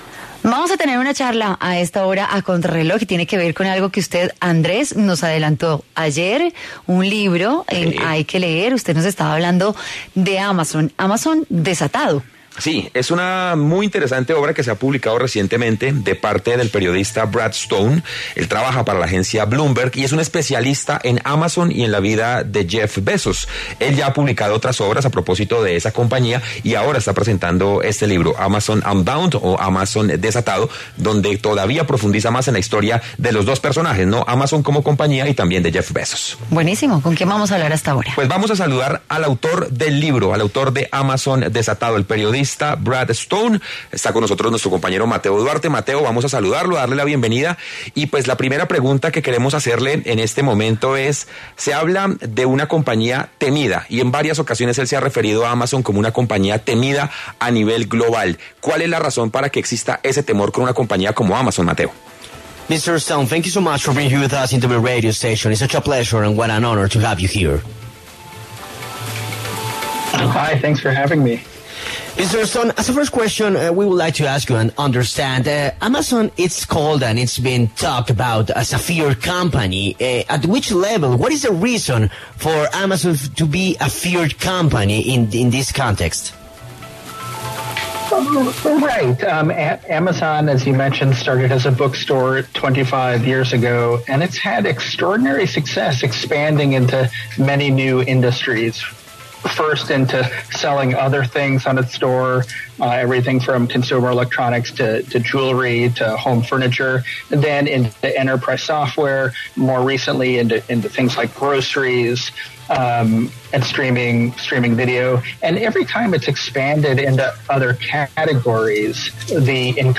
Brad Stone, autor del libro, habló para Contrarreloj de W Radio e indicó que el libro cuenta las distintas consecuencias que trajo consigo la creación de Amazon como una de las empresas internacionales más conocidas a nivel mundial.